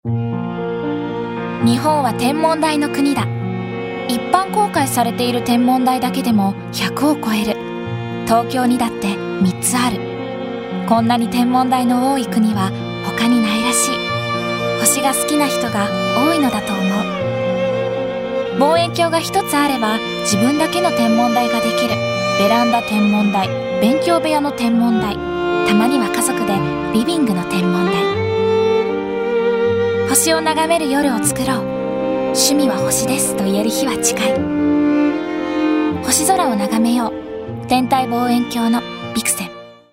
ラジオCM